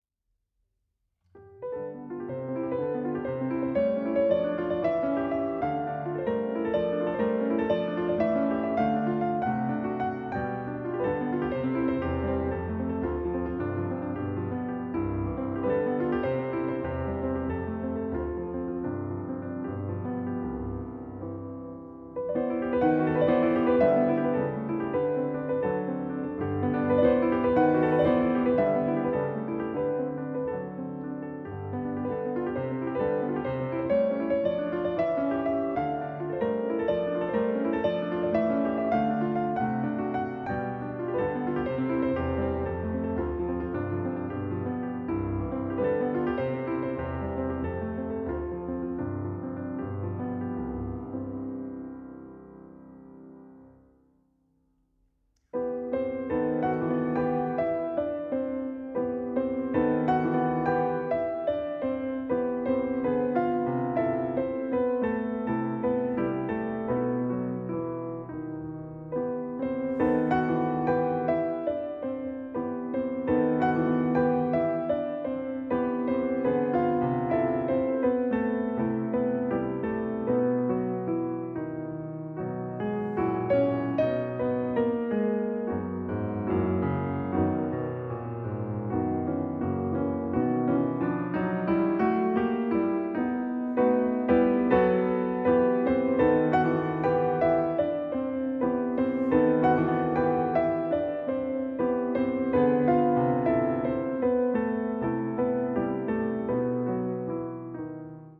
piano
"Léger et tendre"